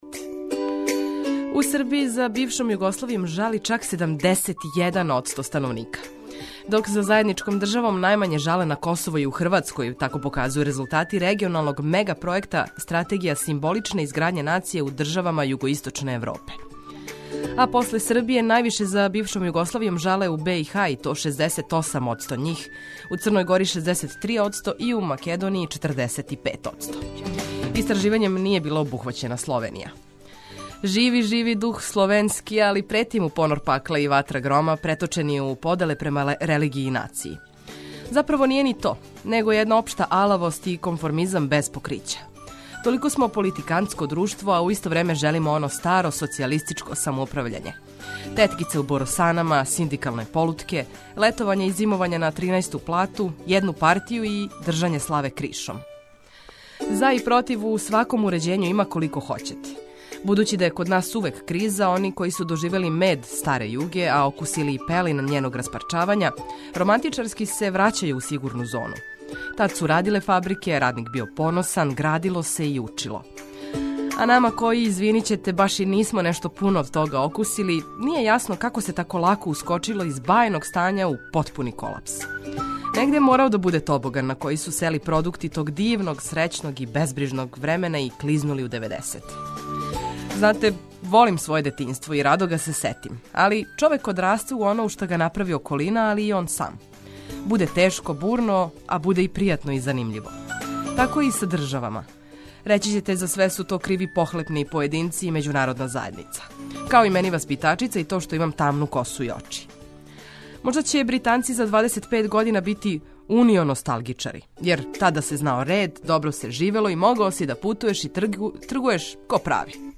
Прве сате јутра испунили смо музиком за разбуђивање и информацијама које су важне.